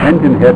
snd_12849_engHit.wav